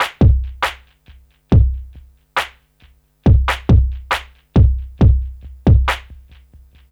C E.BEAT 2-L.wav